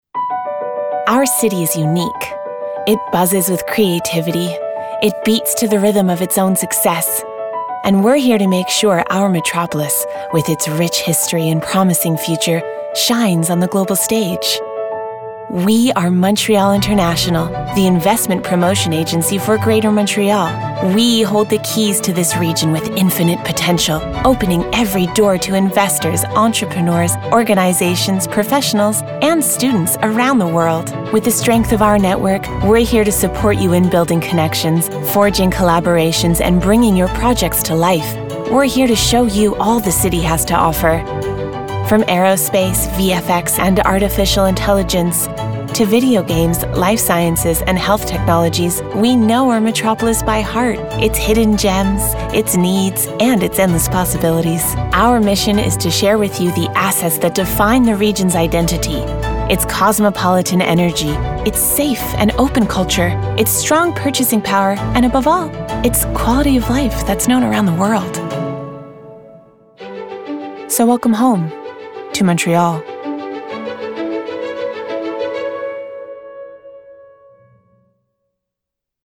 Publicité (Mtl Intnl) - ANG